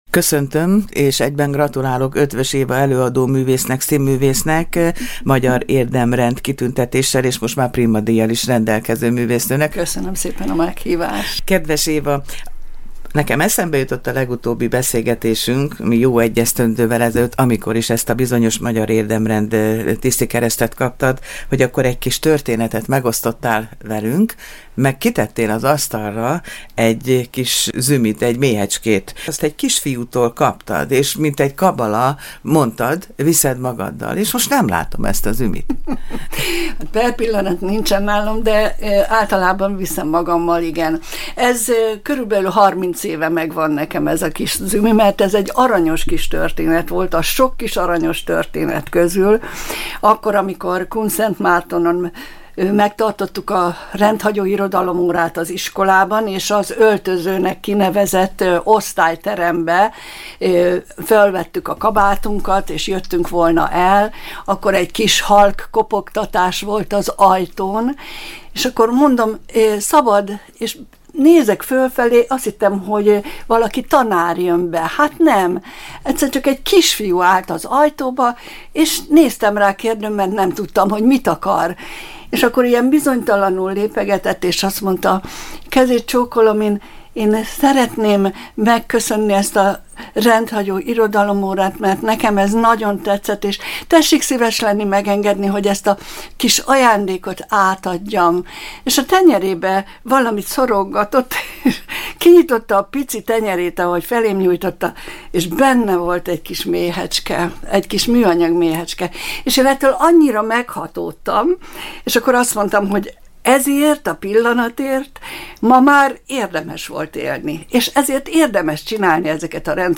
beszélgetés